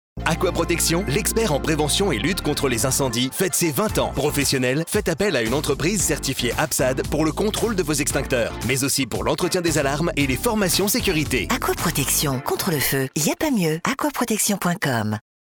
Pub-Audio-kiss-fm.mp3